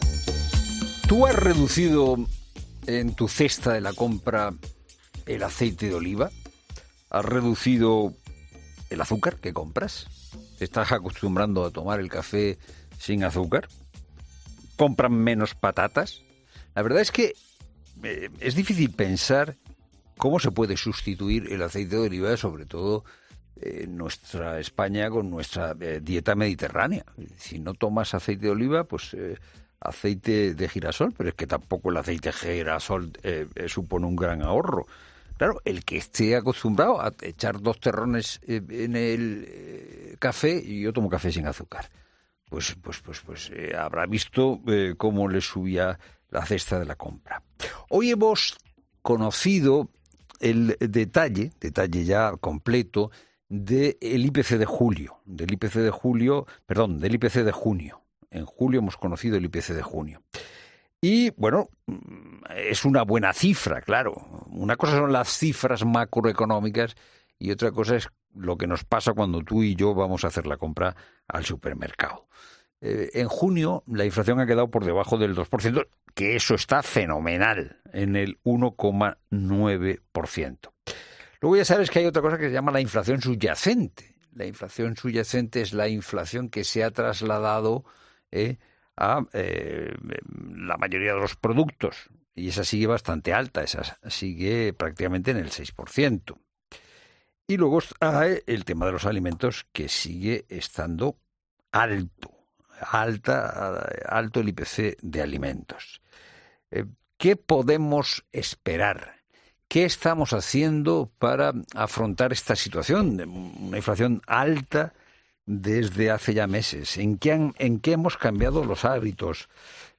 Un catedrático de Economía Aplicada y un profesor de Economía analizan en COPE el IPC de junio y vaticinan cómo será el resto del año